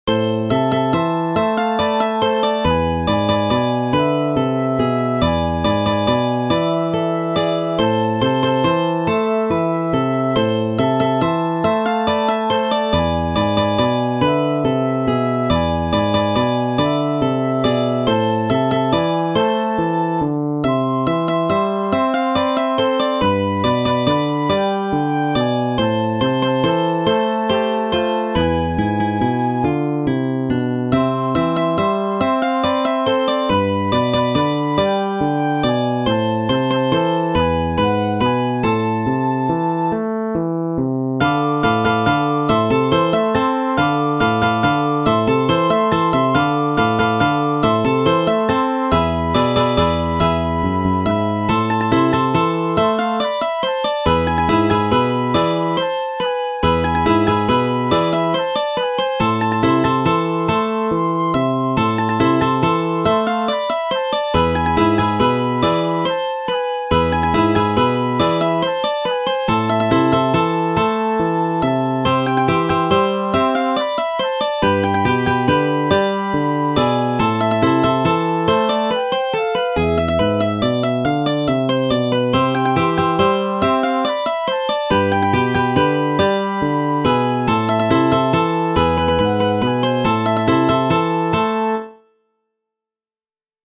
Für 3 Gitarren und Bassgitarre
Ensemblemusik
Quartett
Gitarre (3), Bassgitarre (1)